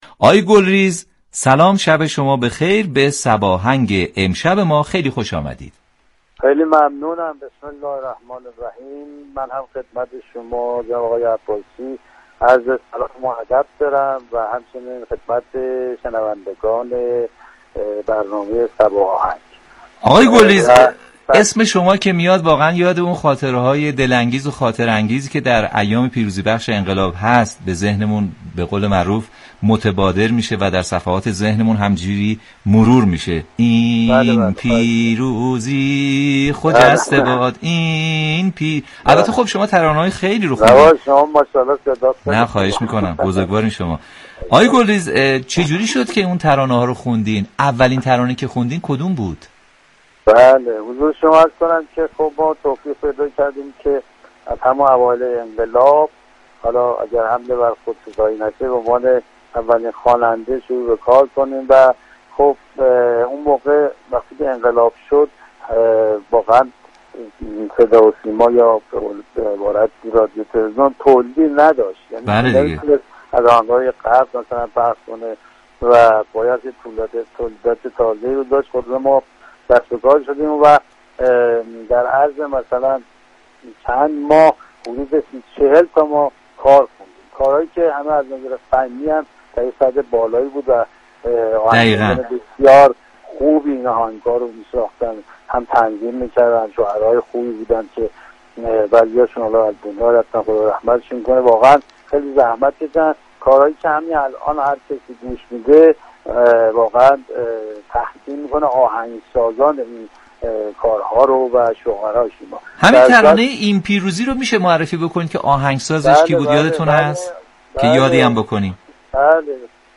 محمد گلریز، در گفتگو با رادیو صبا از خوانندگان قدیمی سرودهای انقلابی می گوید: از سالی كه سرود «خجسته باد این پیروزی» را خواندم هنوز مردم مرا در خیابان آقای پیروزی صدا می كنند!